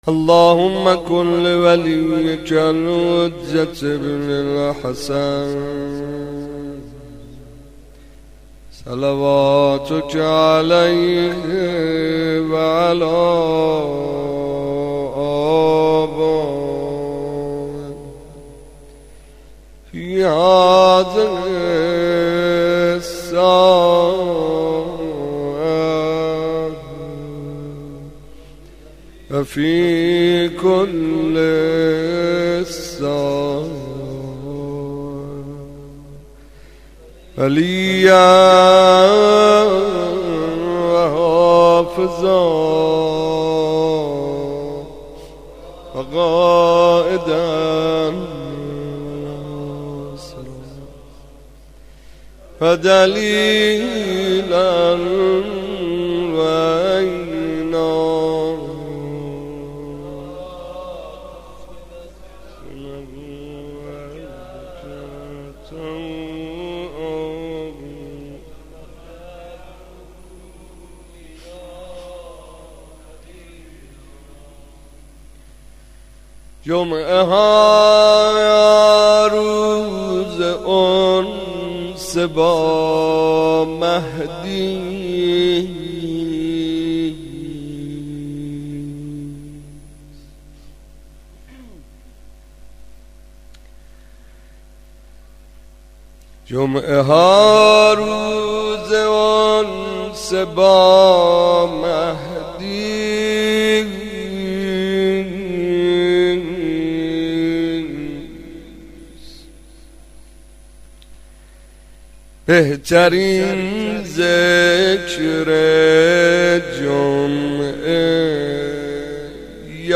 komeil vafat hazrat omolbanin 01.mp3